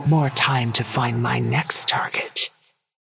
Haze voice line - More time to find my next target.